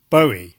It was only relatively recently that I checked and found that the ‘right’ pronunciation has the GOAT vowel, as in low, know and show:
boh-ee.mp3